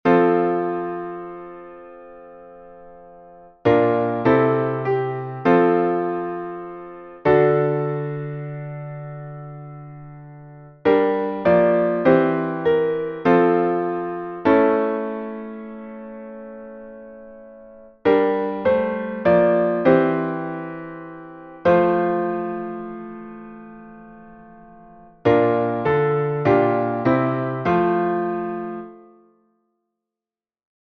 [Chant] (Noble 34323) Composer: T. Tertius Noble Published in 4 hymnals Audio files: Recording Piano/Organ O All Ye Works (Mvt 1 from Festival Bene…
Key: F Major